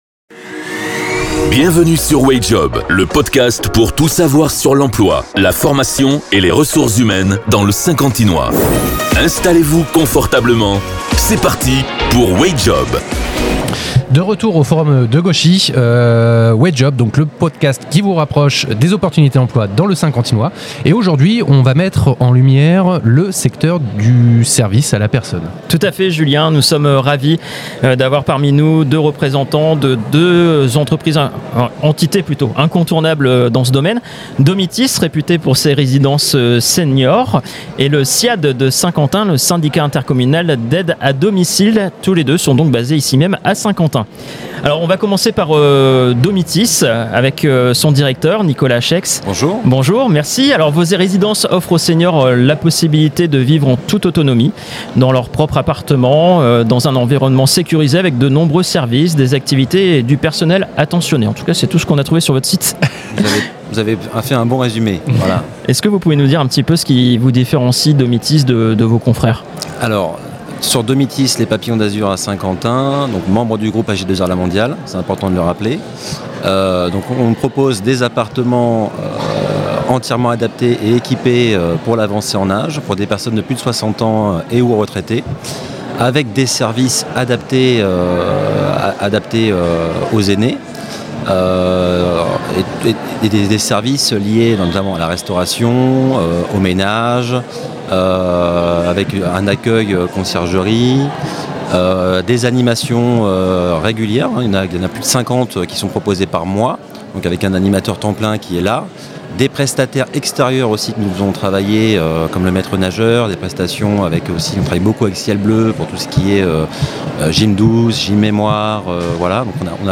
Dans cet épisode de Wayjob , nous organisons une table ronde dédiée au secteur du service à la personne.